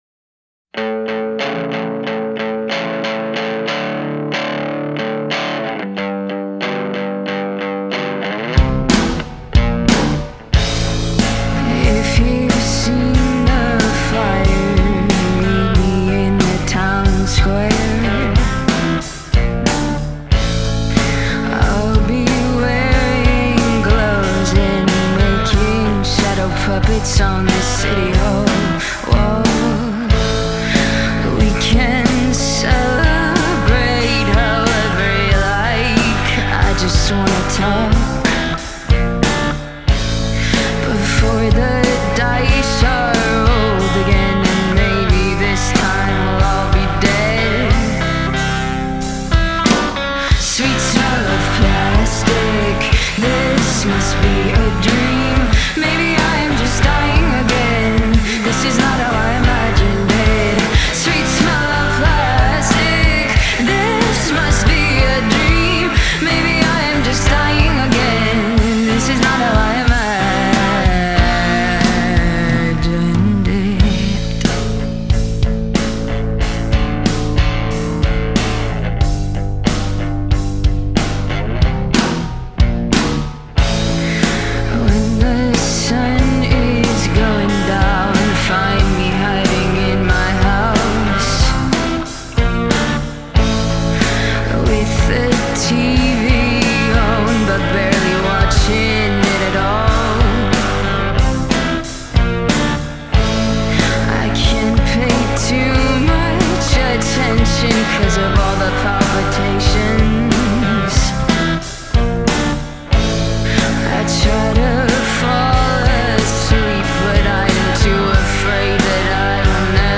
A Boston-based indie rock band
Genre: Indie